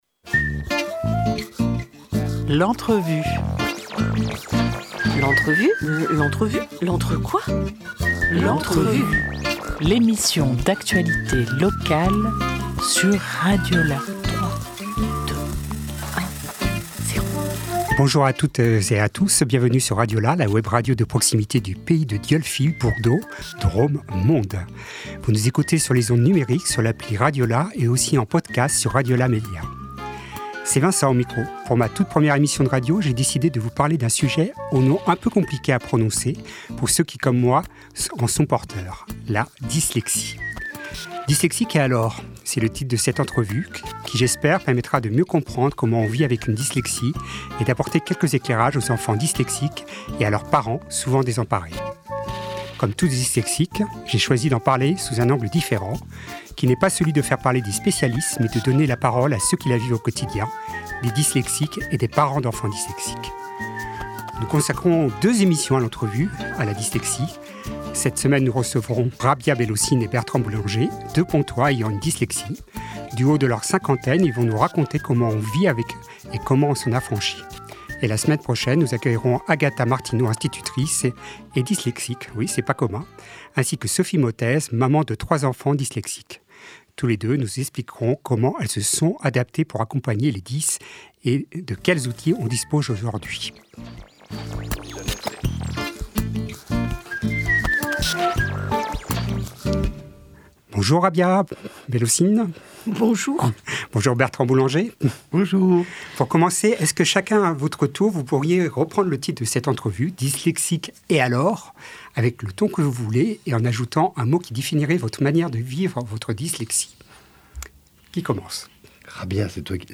24 novembre 2025 9:30 | Interview